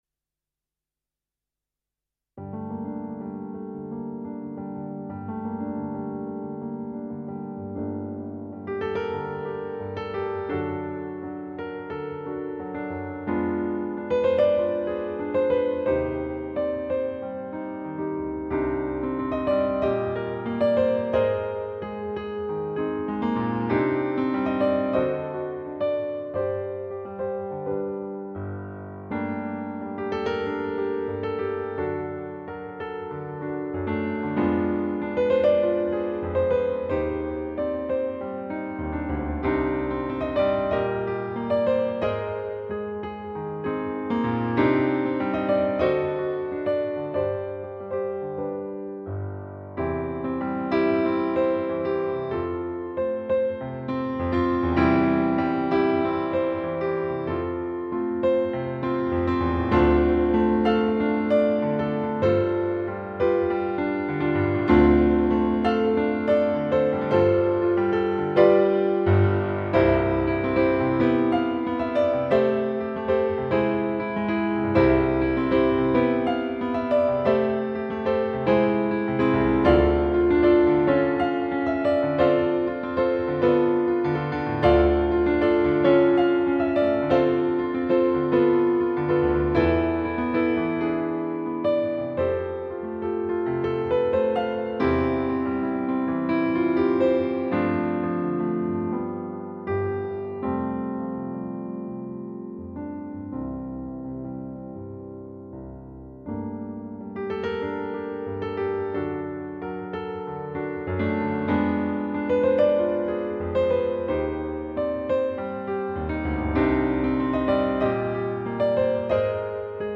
piano - romantique - melancolique - detente - melodique